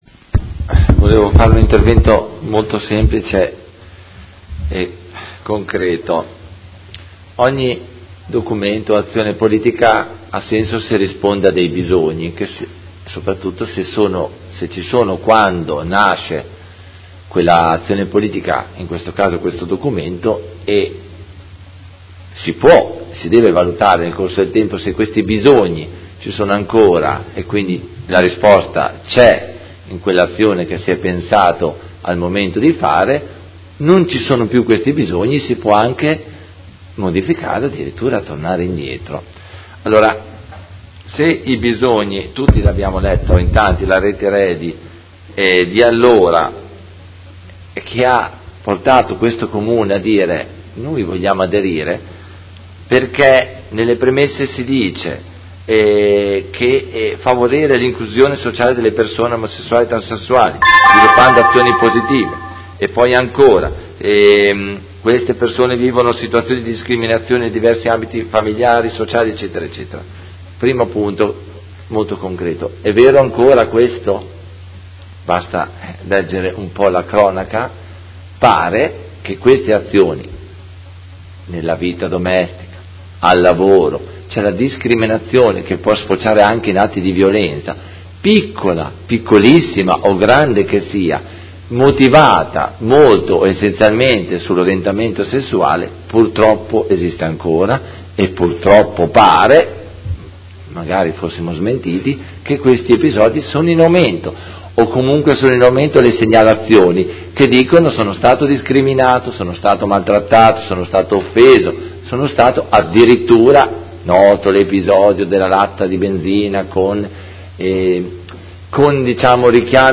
Seduta del 19/09/2019 Dibattito. Prot. Gen. n. 211351 Mozione presentata dalla Consigliera Rossini (FDI-PDF) avente per oggetto: Revoca dell’adesione alla Carta d’Intenti RE.A.DY